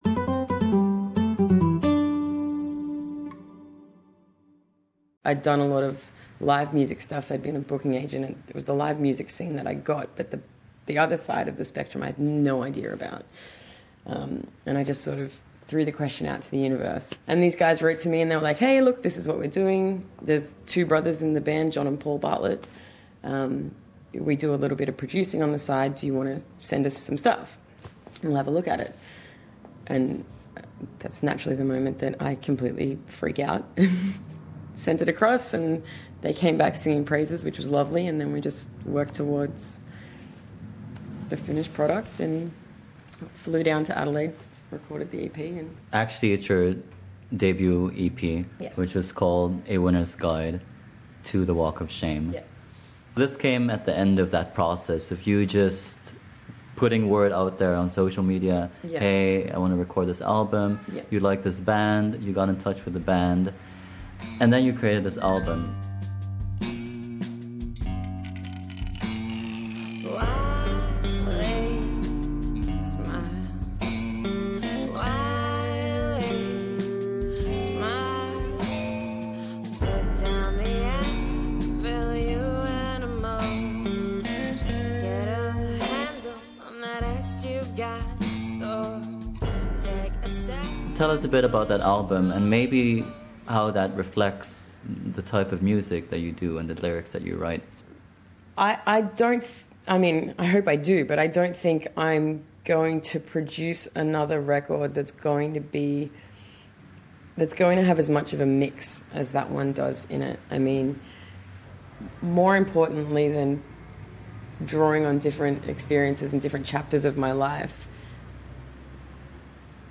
Sa isang pag-uusap